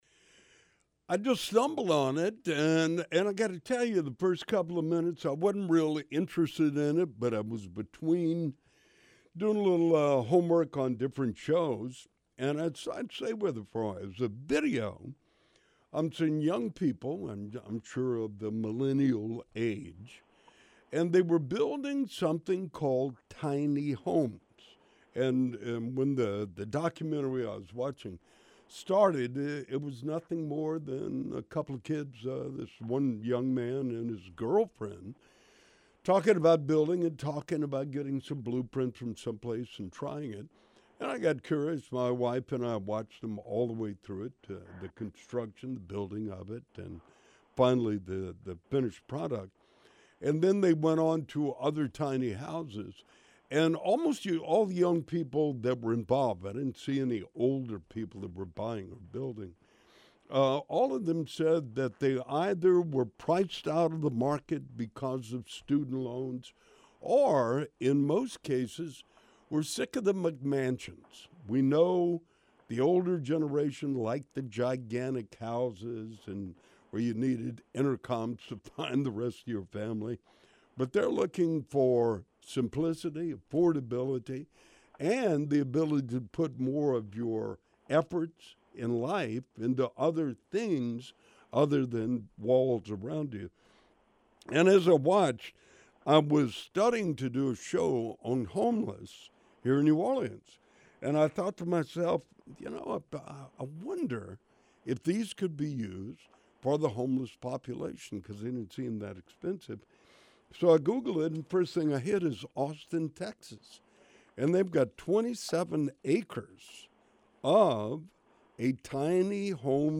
Radio Show – Small House Society
Other guests included tiny house dwellers from across the nation. The show aired 4 March 2015 on National Public Radio (Boston, WBUR).